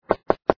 Звуки аськи (ICQ)
• Качество: высокое
Стук в дверь